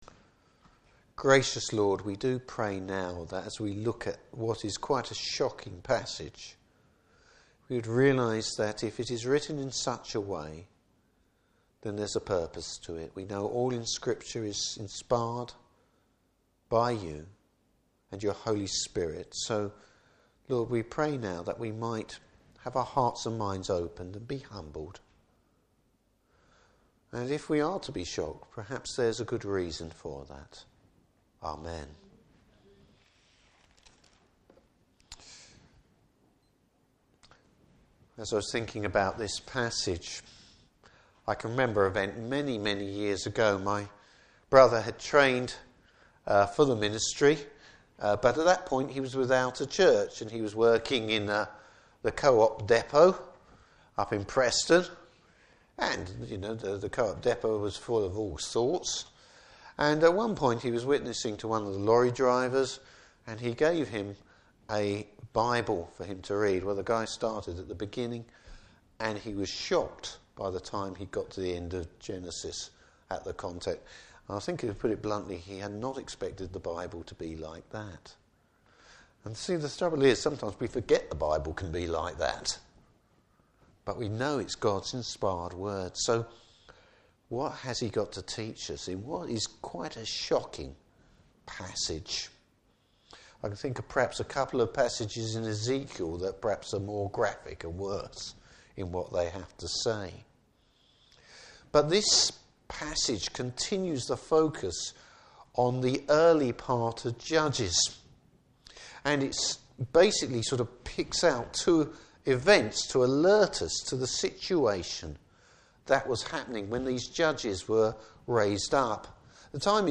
Service Type: Evening Service Bible Text: Judges 19.